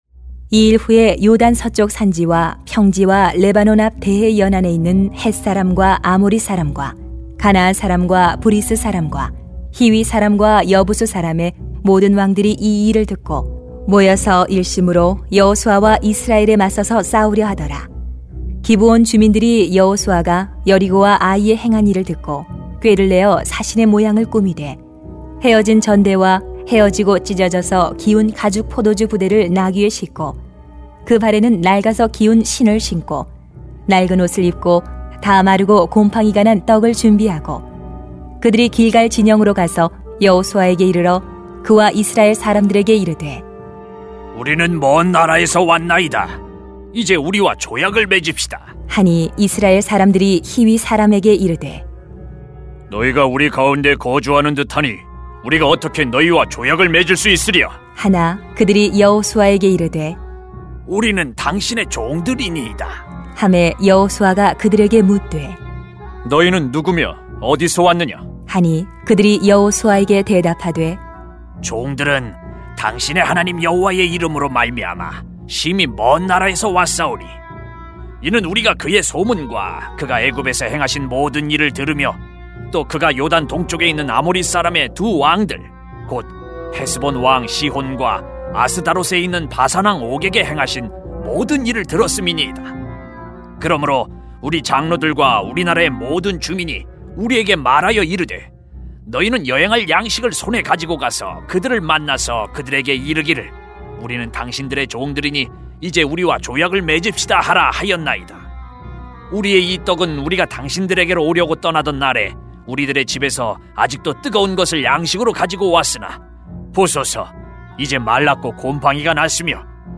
[수 9:1-15] 항상 깨어있어야 합니다 > 새벽기도회 | 전주제자교회